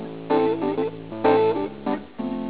Tous les instruments de musique fonctionnent sur ces 5 principes selon lesquels on peut les classer. Aux moyens traditionnels de produire des sons, le XXème siècle a ajouté l'électricité.